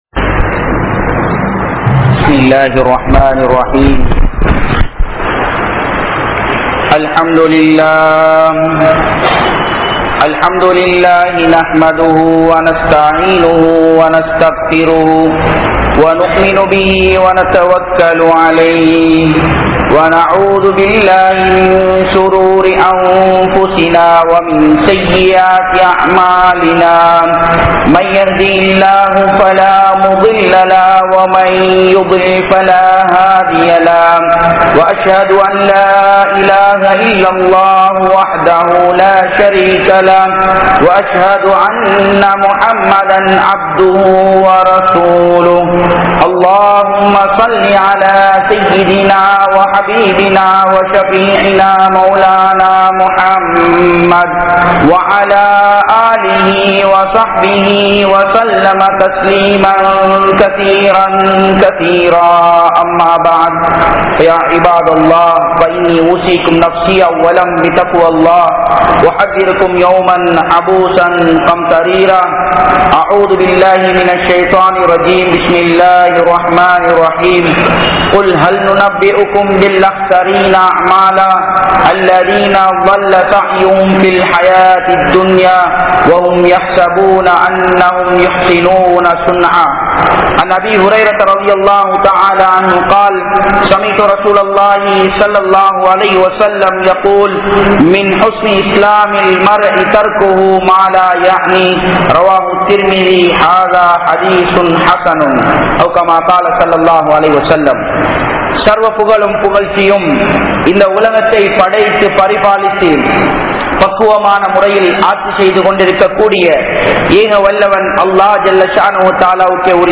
21aam Noottraantin Manitharhalukku Eatpattulla Puthia Boathai (21ஆம் நூற்றான்டின் மனிதர்களுக்கு ஏற்பட்டுள்ள புதிய போதை) | Audio Bayans | All Ceylon Muslim Youth Community | Addalaichenai